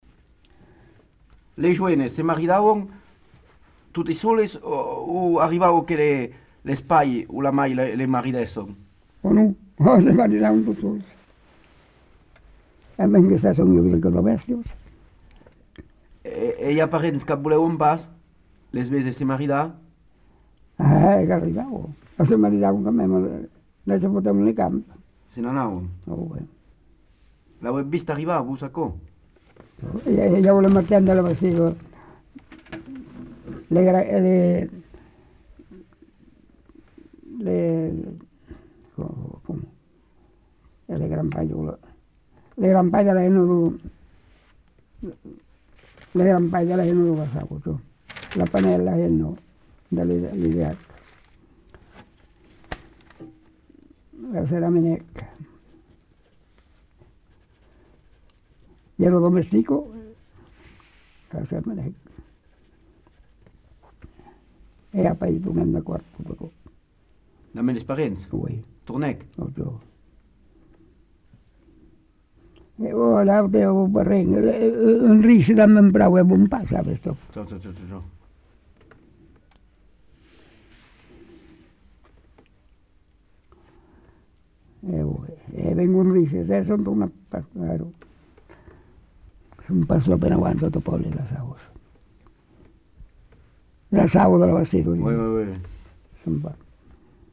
Lieu : Pompiac
Genre : témoignage thématique